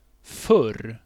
Uttal
Uttal Okänd accent: IPA: /fœr/ Ordet hittades på dessa språk: svenska Ingen översättning hittades i den valda målspråket.